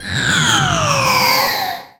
Cri de Lugulabre dans Pokémon X et Y.